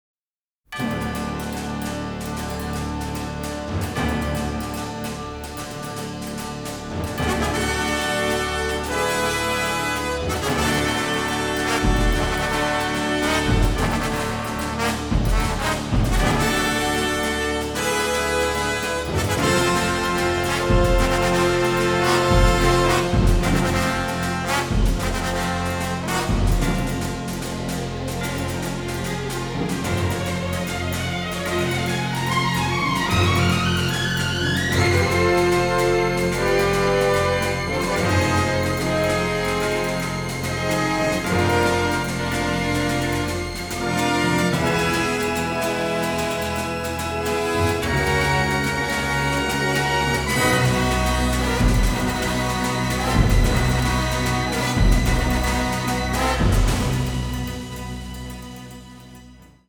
classic war score